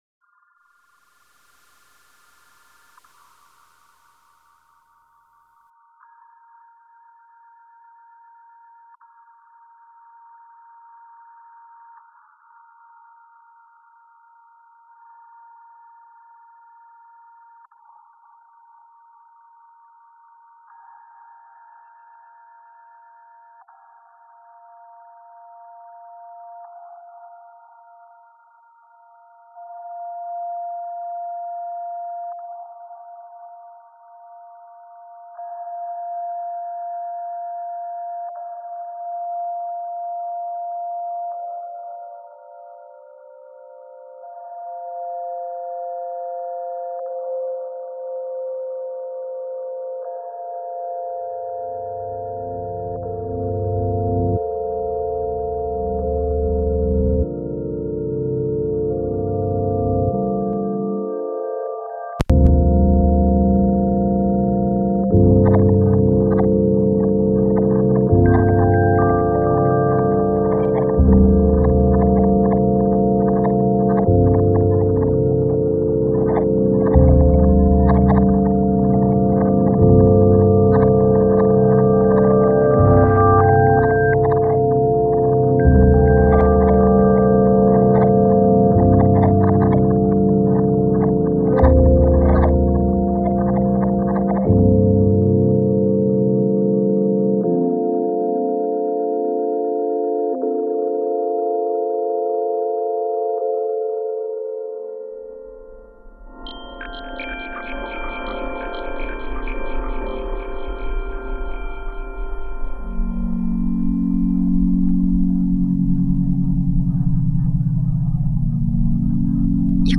on the instrumental and vocal side respectively.